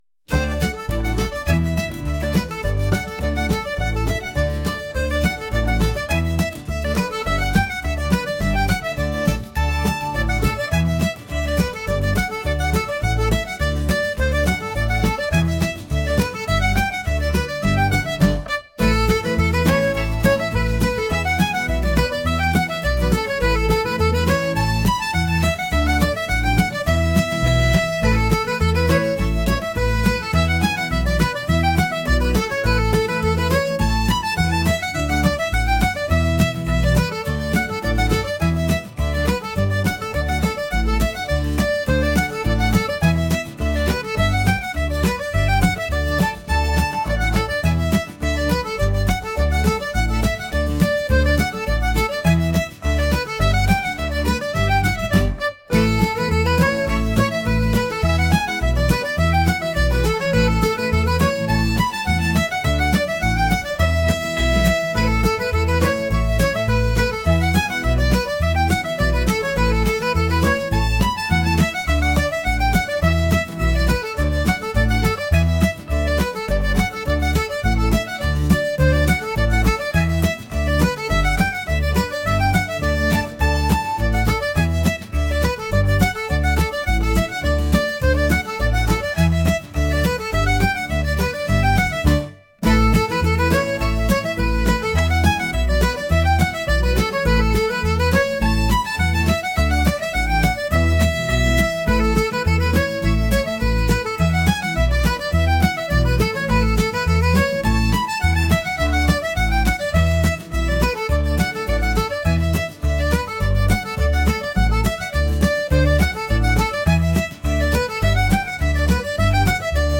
folk | energetic